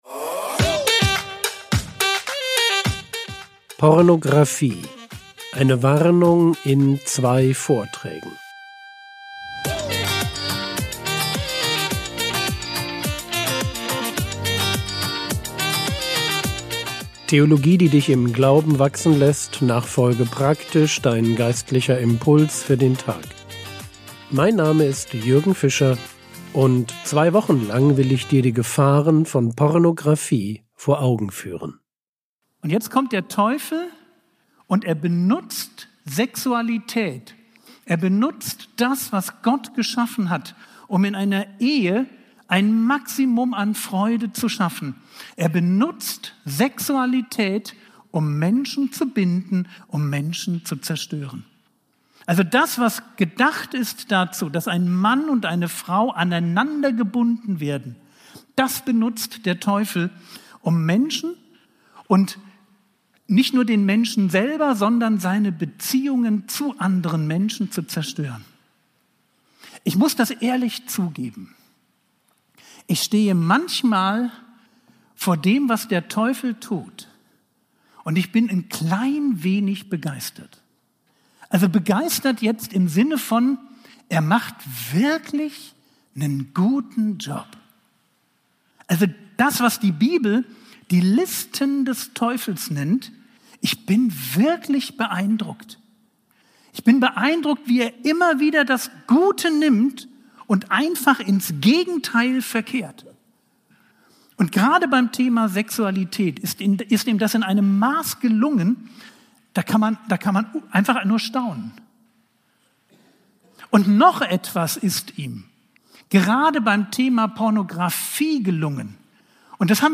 Frogwords Mini-Predigt